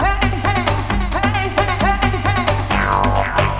Index of /90_sSampleCDs/E-MU Formula 4000 Series Vol. 2 – Techno Trance/Default Folder/Tribal Loops X